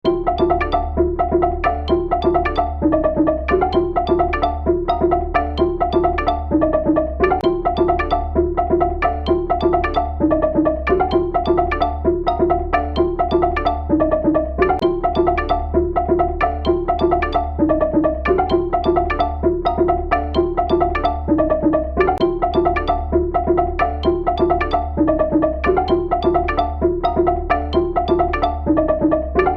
マリンバの着信音の楽しい旋律。